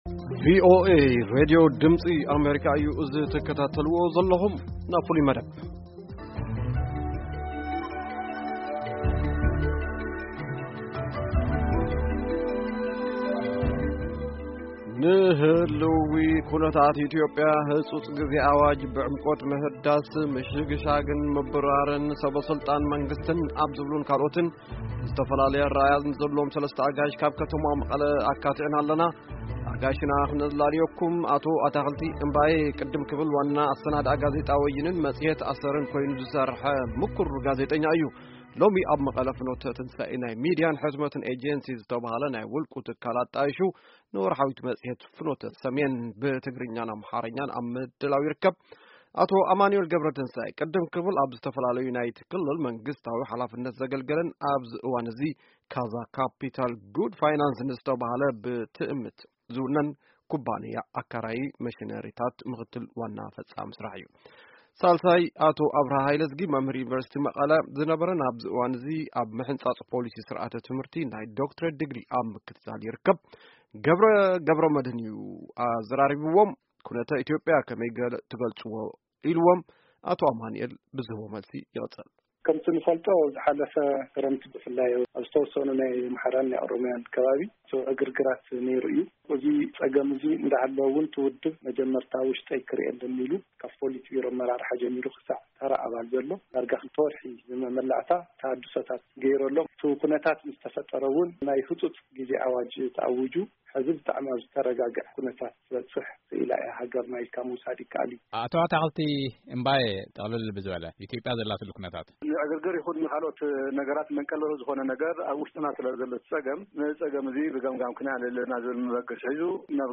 ክትዕ ኣብ ኩነተ ኢትዮጵያ